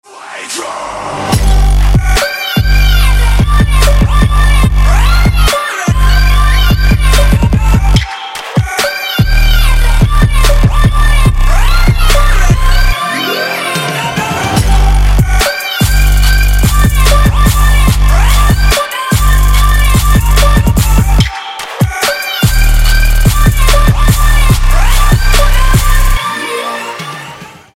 • Качество: 256, Stereo
Trap
трэп